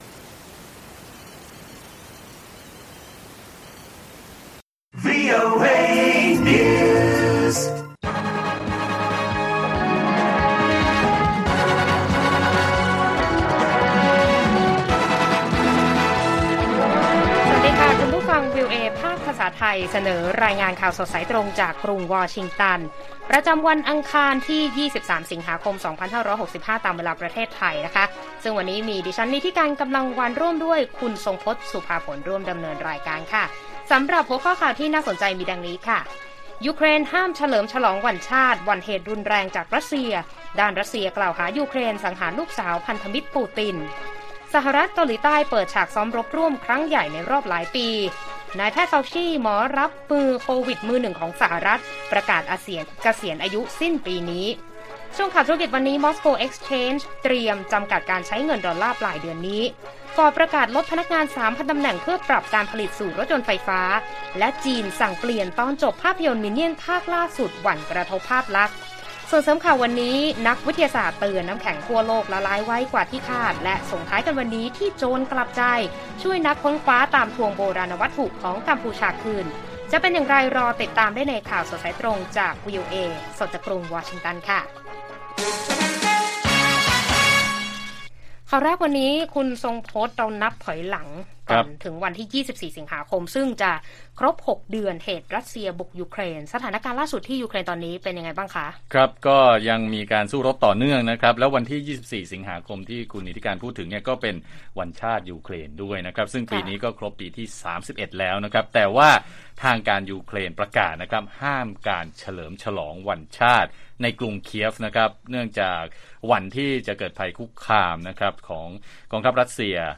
ข่าวสดสายตรงจากวีโอเอ ไทย อังคาร 23 ส.ค. 65